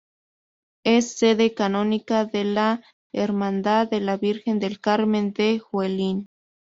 Pronounced as (IPA) /ˈkaɾmen/